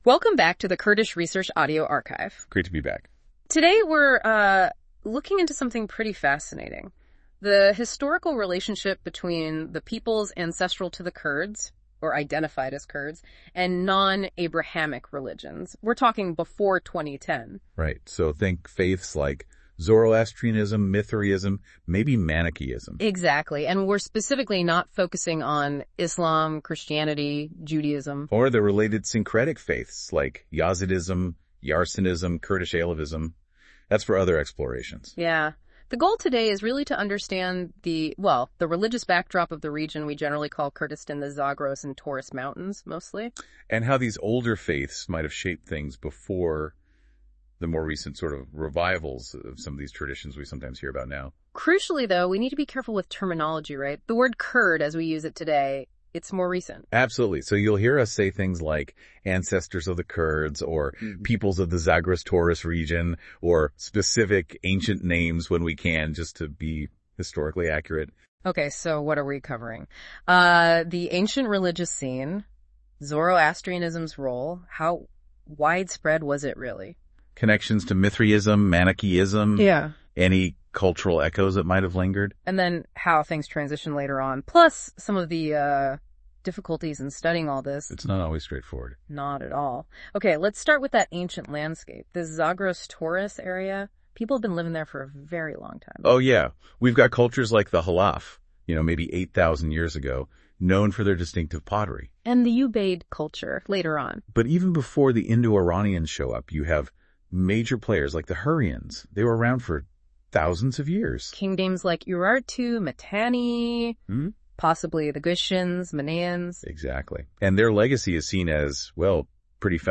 Note: This was made with AI research and AI audio output, and does not conform to academic standards.